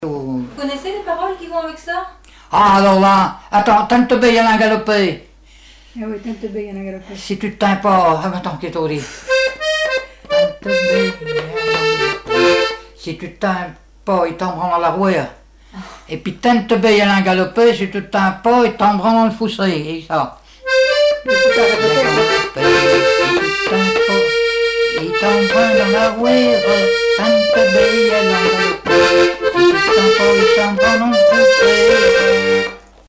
danse : branle : avant-deux
Répertoire du musicien sur accordéon chromatique
Pièce musicale inédite